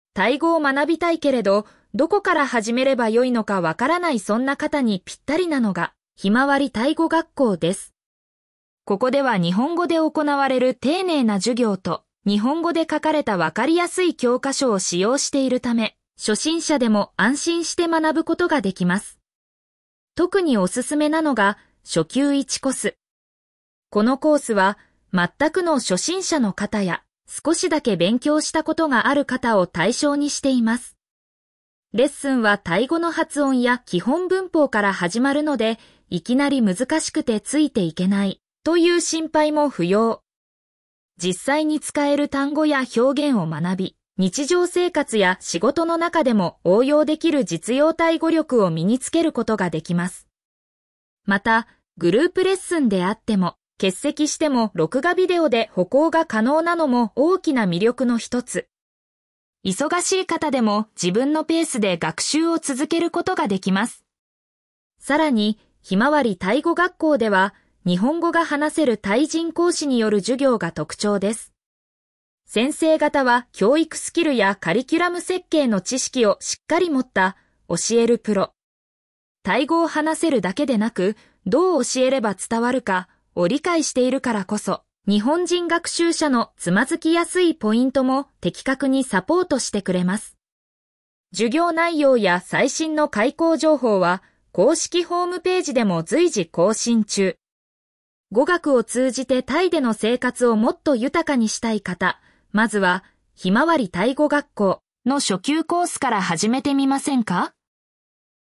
ペルプ バンコク・メディプレックスビルディング リンク先 「ひまわりタイ語学校」授業風景 グループ 「ひまわりタイ語学校」授業風景 個人 読み上げ タイ語を学びたいけれど、どこから始めればよいのかわからない──そんな方にぴったりなのが、「ひまわりタイ語学校」です。